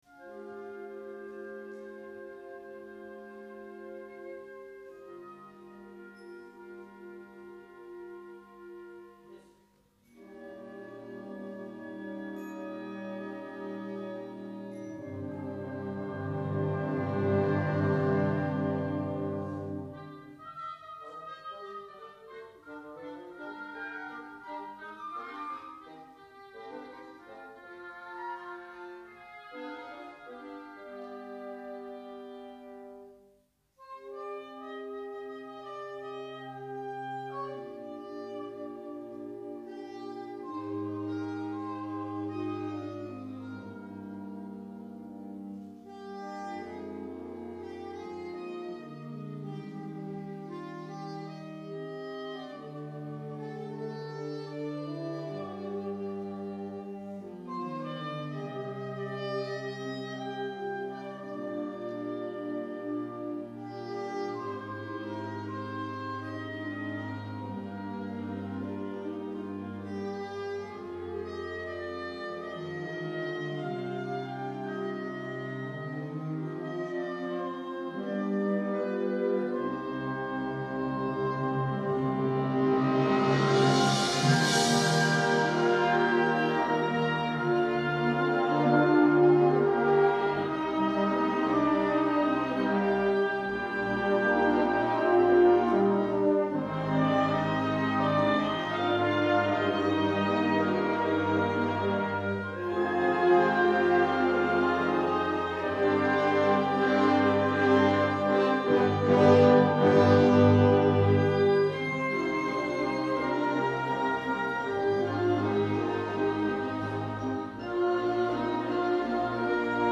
7月3日合奏練習
栄公会堂